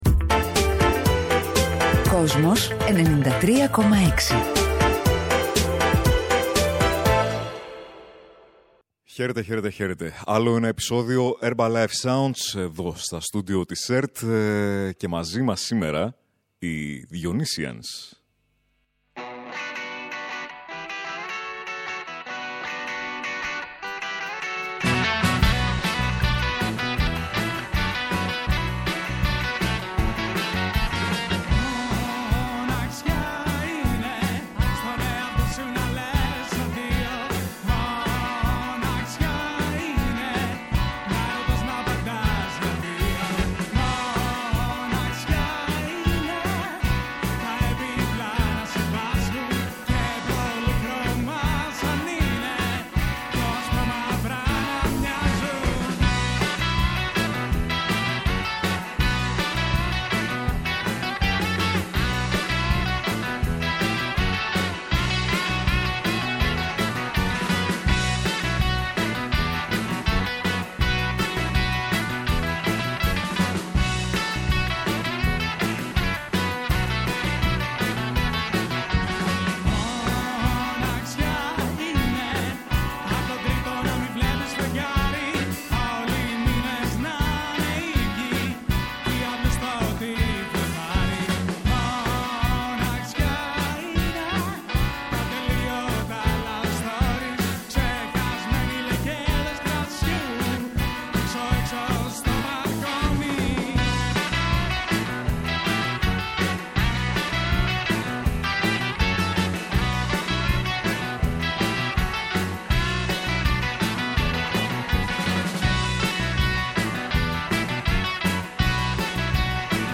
Με ελληνικό στίχο και ακατέργαστο ήχο